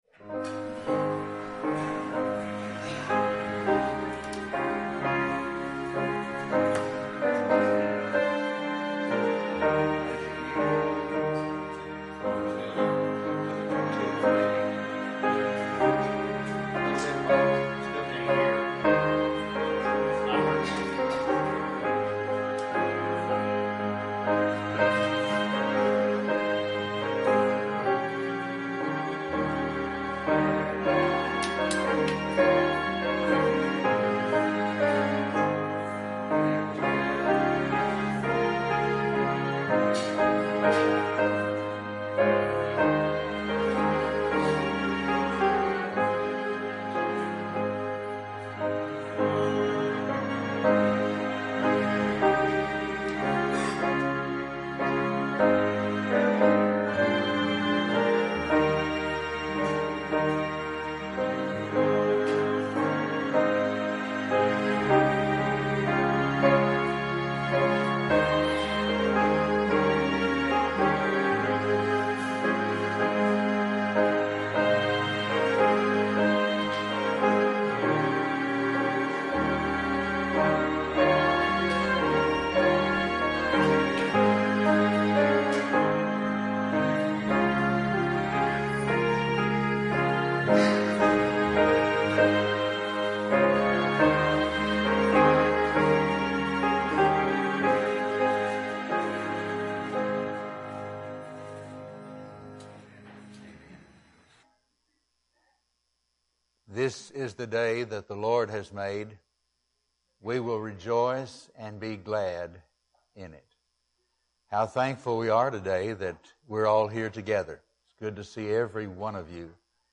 Morning message from Genesis 1.